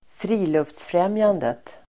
Uttal: [²fr'i:luftsfrem:jandet]